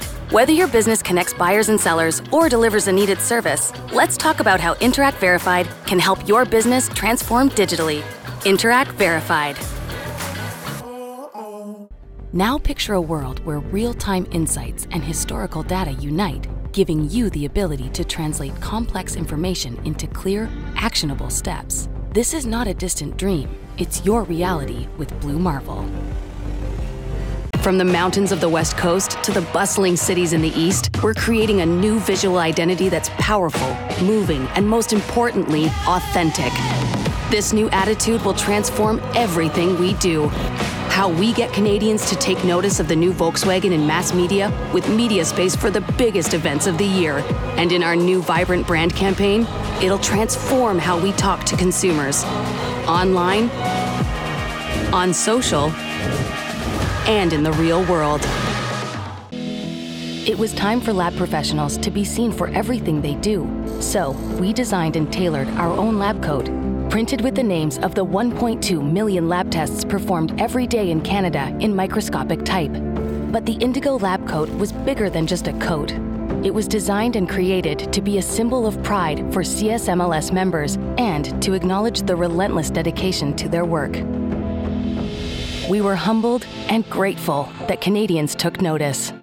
Female
Corporate
Conversational, Professional